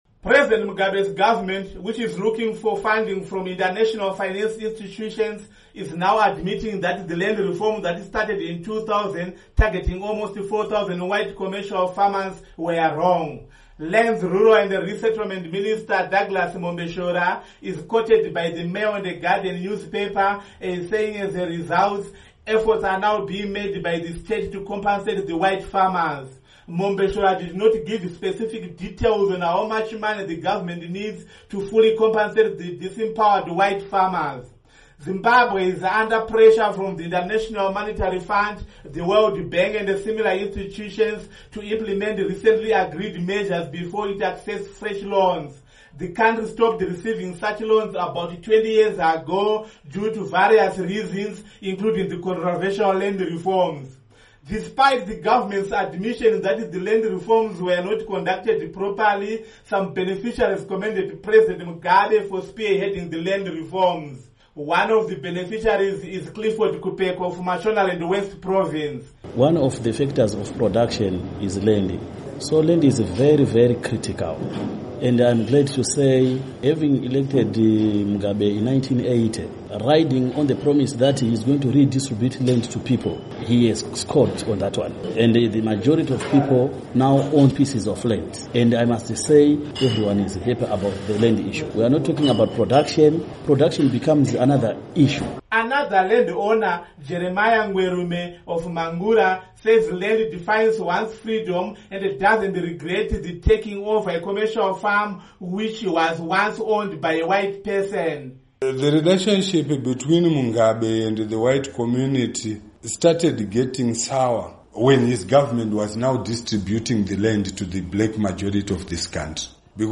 Report on Land Reforms in Zimbabwe